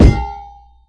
sounds / weapon / casing / large3.ogg